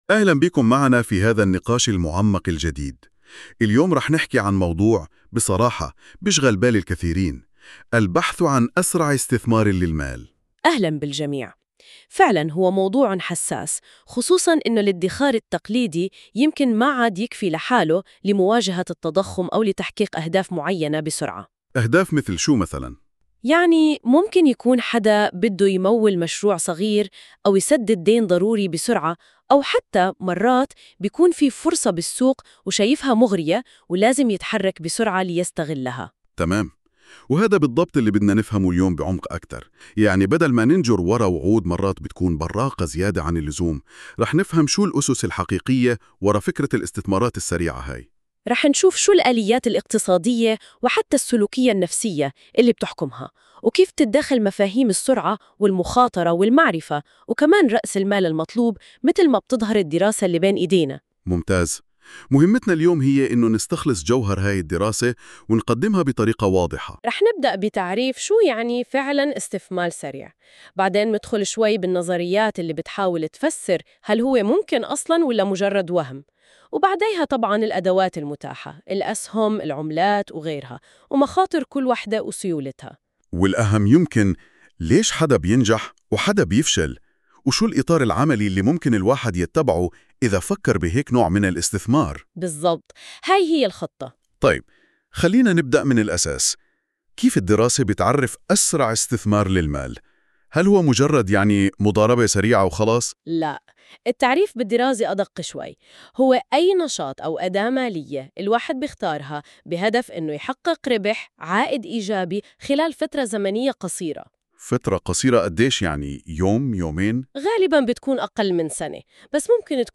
يمكنك الاستماع إلى هذا المقال بدلاً من قراءته عبر المقطع الصوتي التالي👇